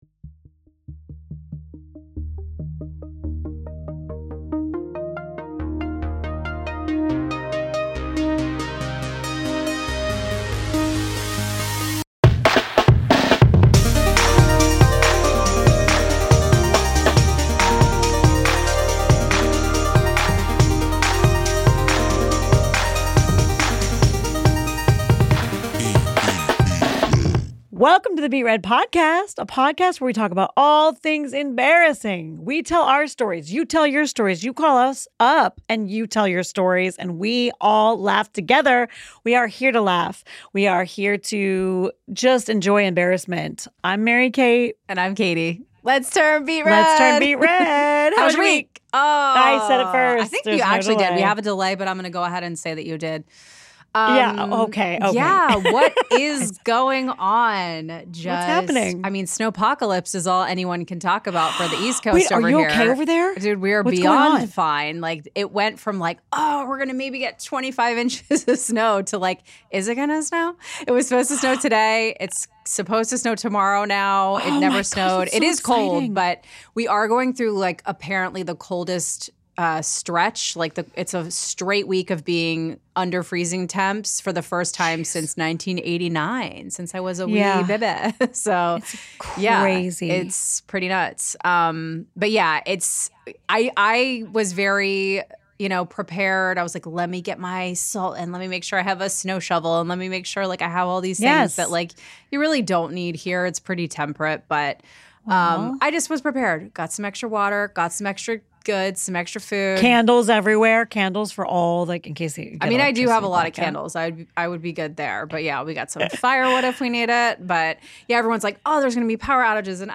at PROJKT studios in Monterey Park, CA.